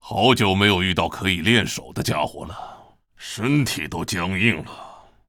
文件 文件历史 文件用途 全域文件用途 Hartz_amb_02.ogg （Ogg Vorbis声音文件，长度5.3秒，105 kbps，文件大小：68 KB） 源地址:地下城与勇士游戏语音 文件历史 点击某个日期/时间查看对应时刻的文件。